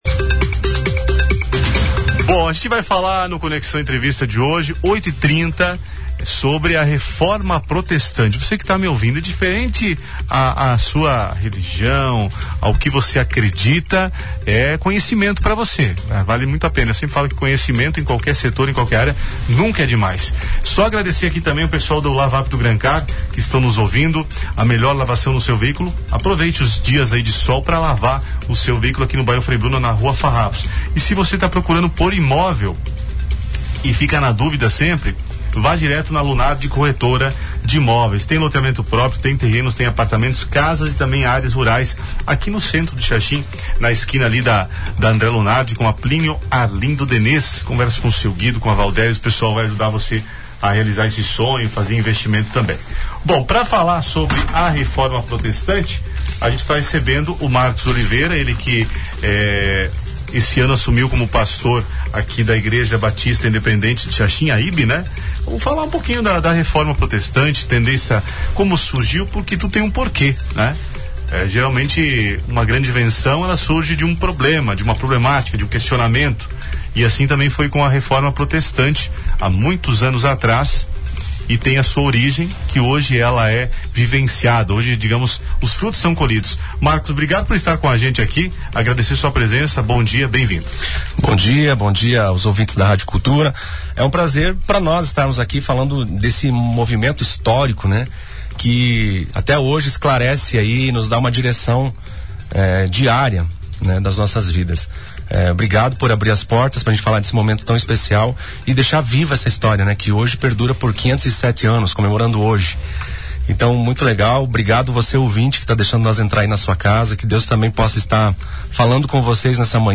ENTREVISTA-31-10.mp3